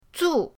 zu4.mp3